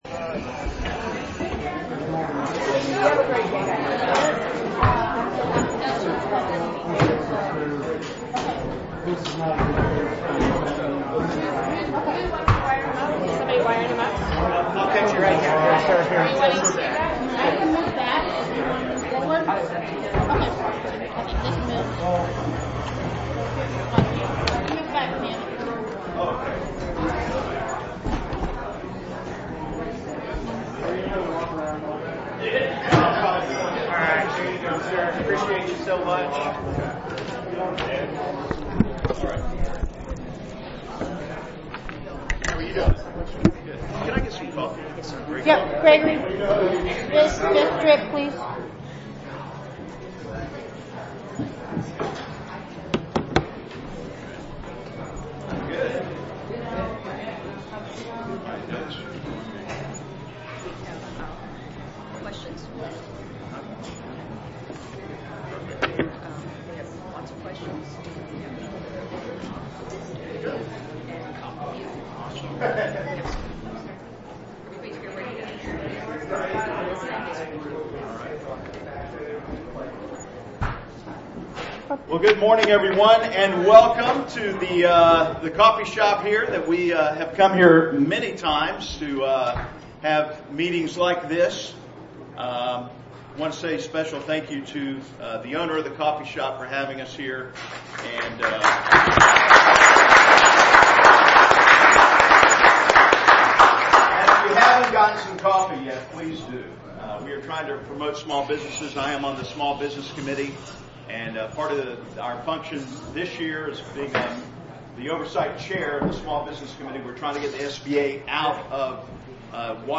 This morning, starting at 8:30 a.m., Mark Alford (r) hosted a constituent coffee at an establishment in downtown Belton, Missouri.
The venue was crowded and there was an overflow crowd on the sidewalk at the entrance door. The crowd outside started chanting and two police officers were directed to close the door.
Mark Alford (r) was easily able to continue speaking over her protest – probably from his years of experience having a producer in his earpiece.
The pushback from the crowd became more agitated as the event progressed.